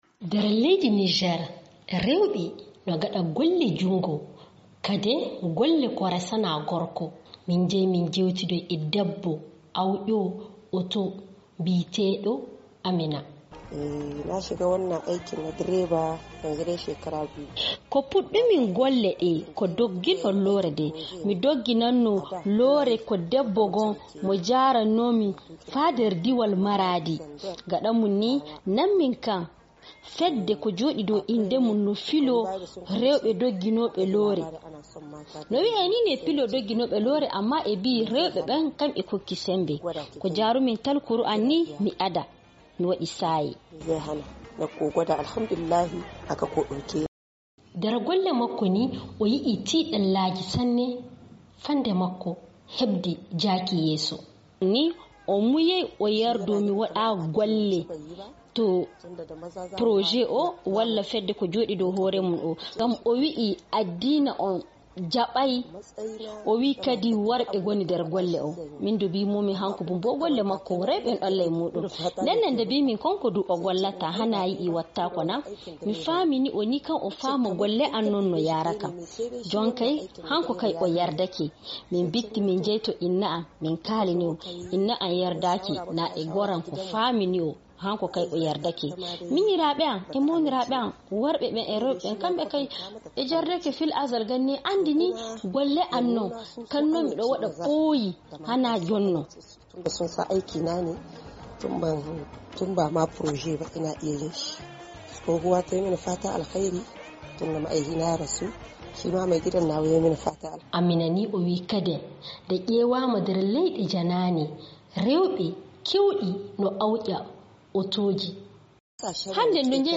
Ñaamey, NIG: jantoore e dow debbo diginoowo/awƴoowo taxi